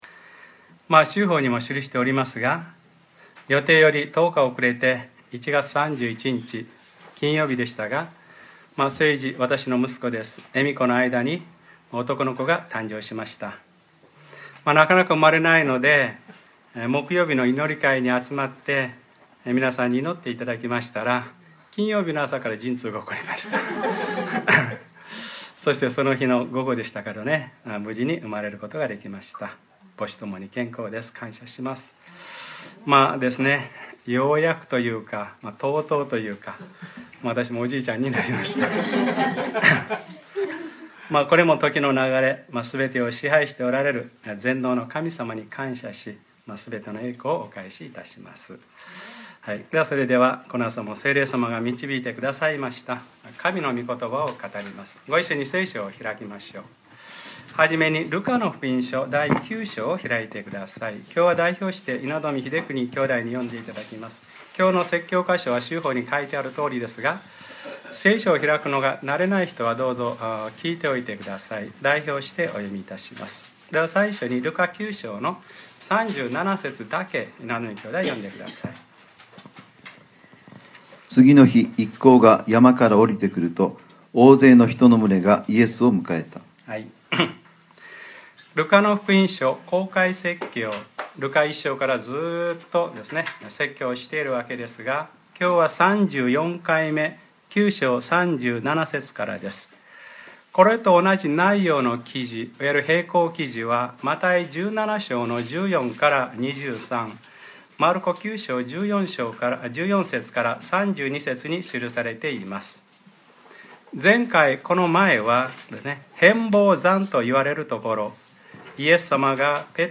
2014年 2月 2日（日）礼拝説教『ルカ-３４：イエス様中心』